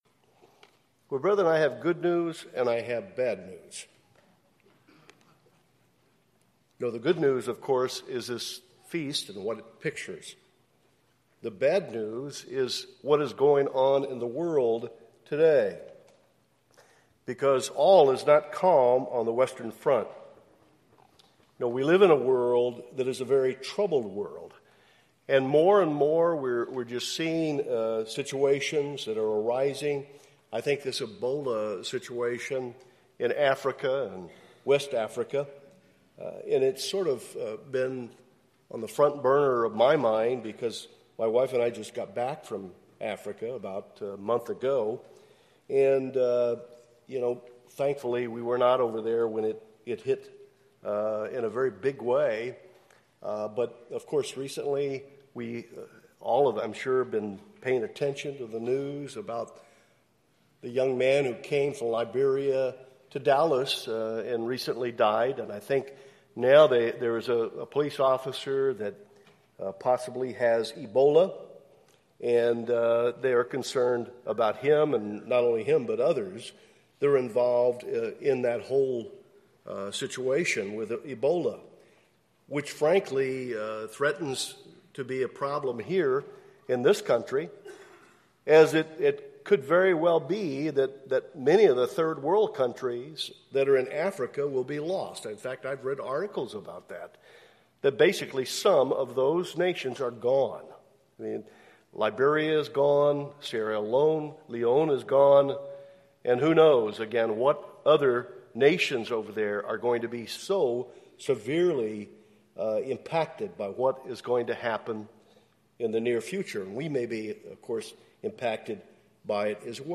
This sermon was given at the Lihue, Hawaii 2014 Feast site.